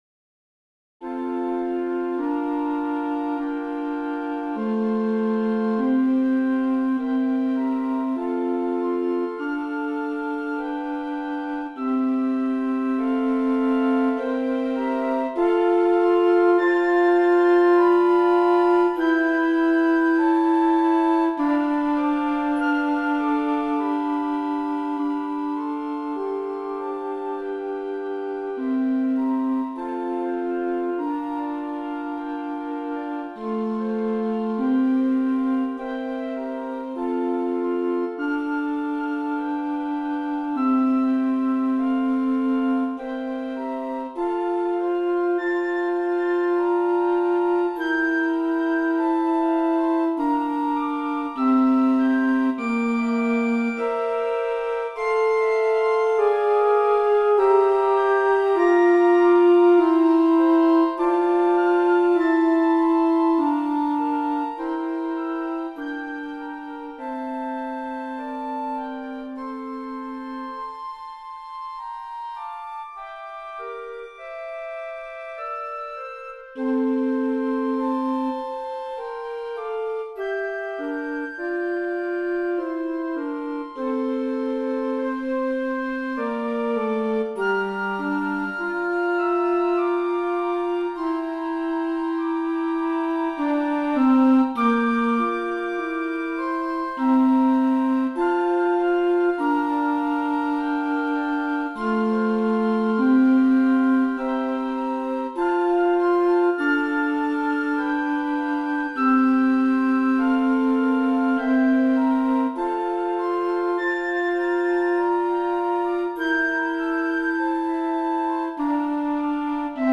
Voicing: Flute Ensemble